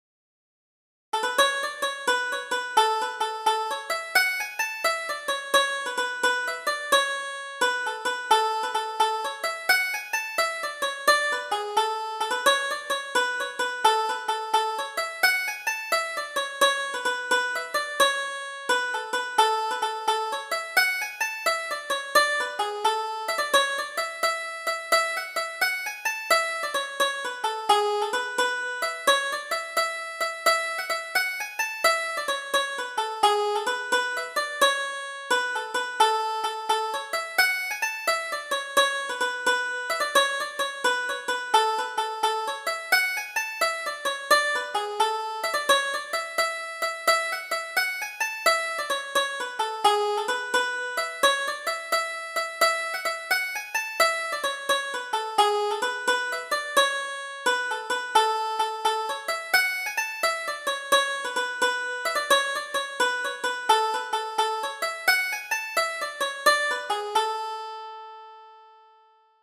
Double Jig: Nothing Can Sadden Us